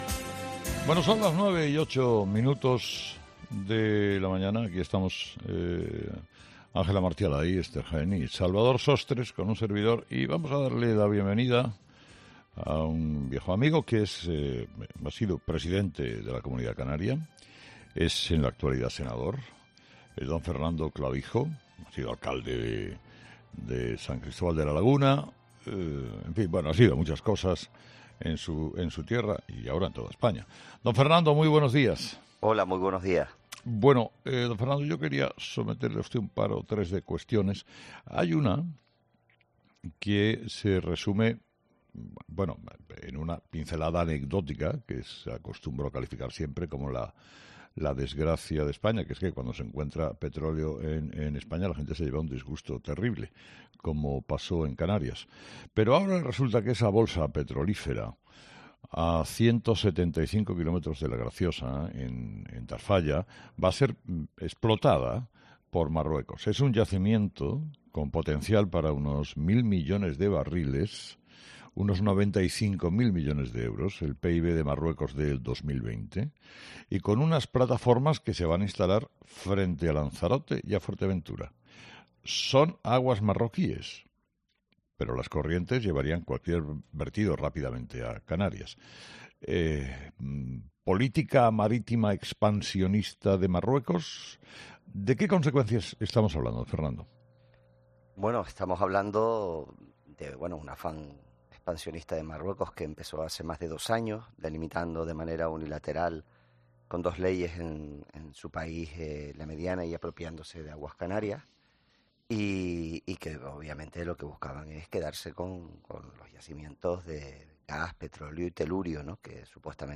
El secretario general de Coalición Canaria y senador afirma en COPE que España ha cedido al chantaje de Marruecos